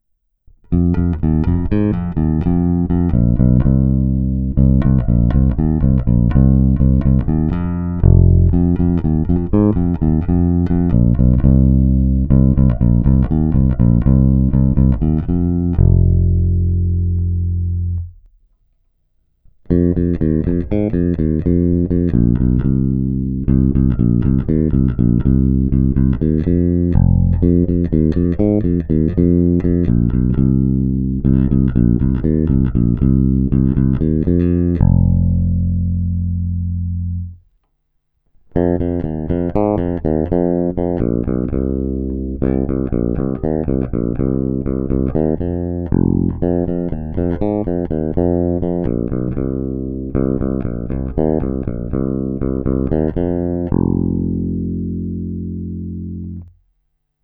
Zvuk je standardní, očekávatelný při dané koncepci, velice dobře hraje i struna H, a to jsem použil dokonce vintage typ hlazených strun.
Není-li uvedeno jinak, následující nahrávky jsou provedeny rovnou do zvukové karty, jen normalizovány, jinak ponechány bez úprav.